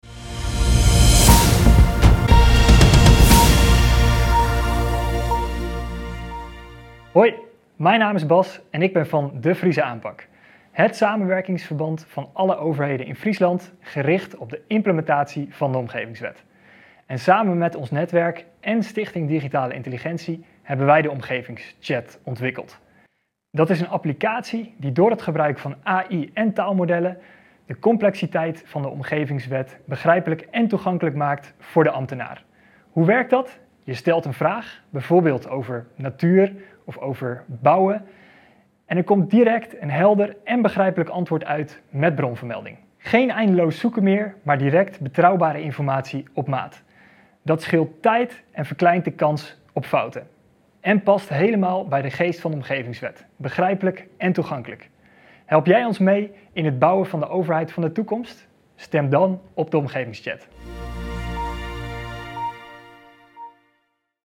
Pitch OmgevingsChat